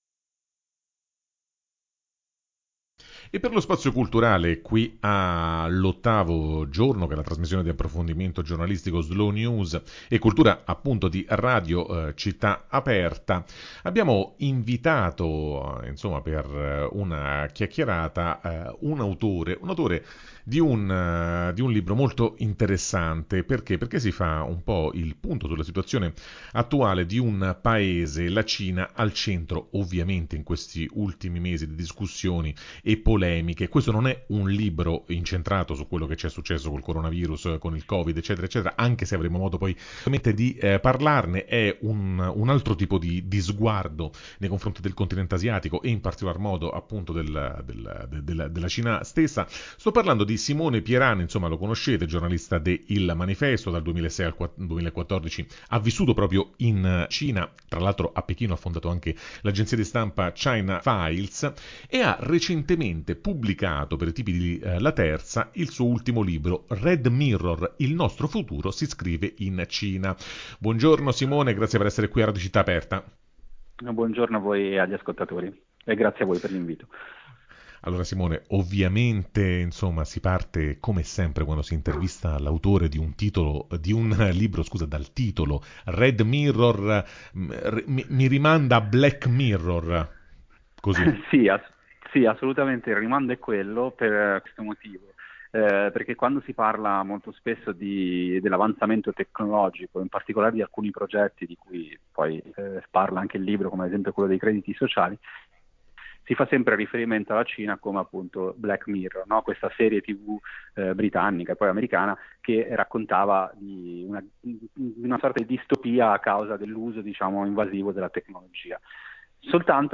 Red Mirror – nel futuro cinese c’è scritto anche il tuo nome [Intervista